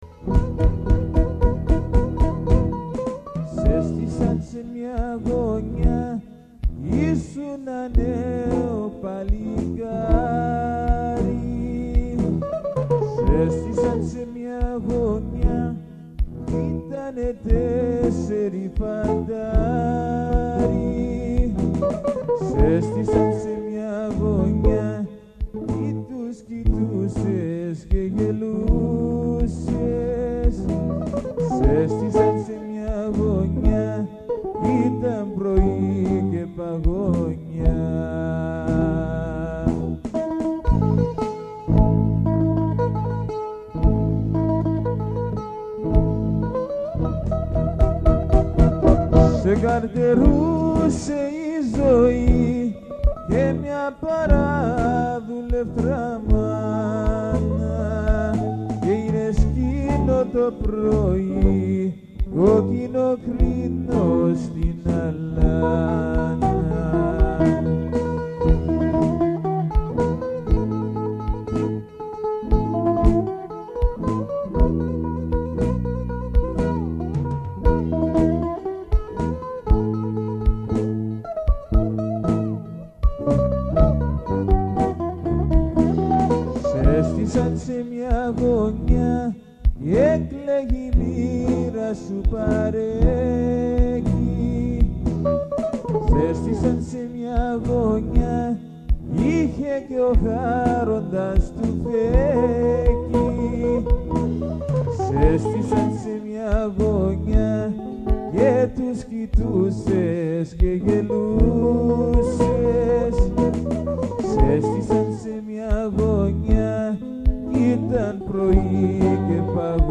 With "The Parea" - Traditional Greek music, these covers were recorded during a practice session.
Most of the music is in 2/4, 7/8 or 9/8 and very rewarding to play because everybody dances.
bouzouki
acoustic guitar
*No bitching about the sound quality on any of these, I was the guy playing drums not the guy running sound*
parea - zeimbeiko.mp3